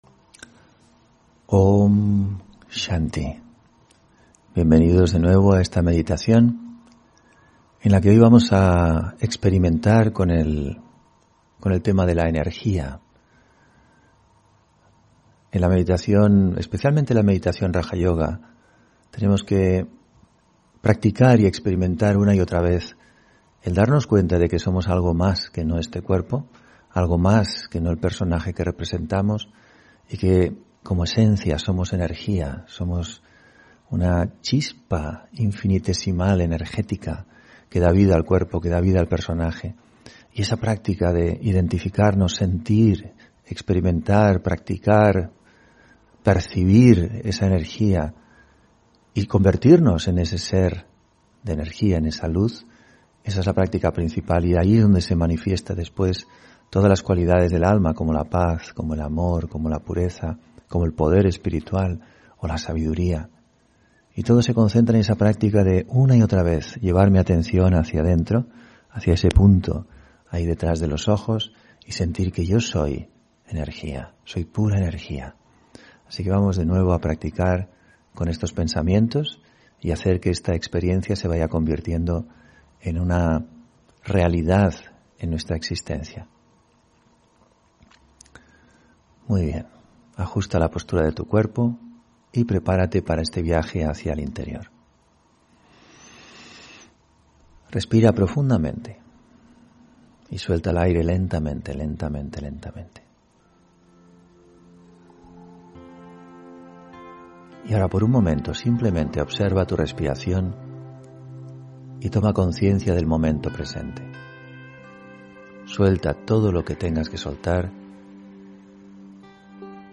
Meditación de la mañana: Yo soy energía pura